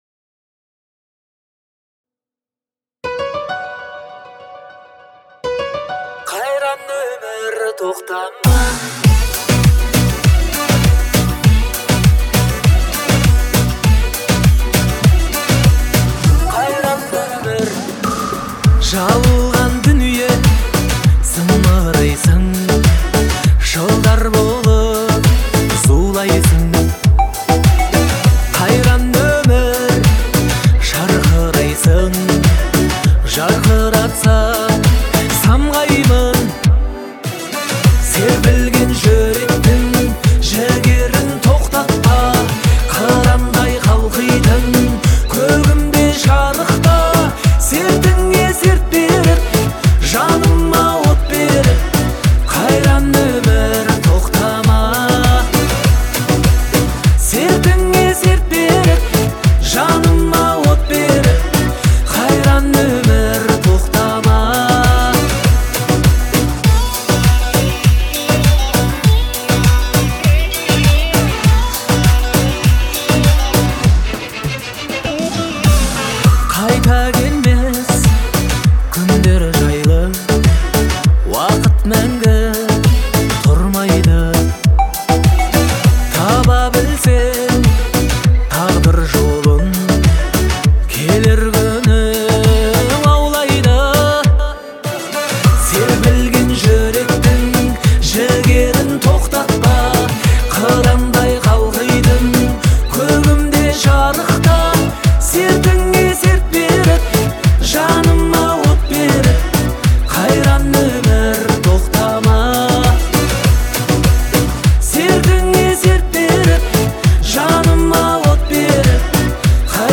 казахской поп-музыки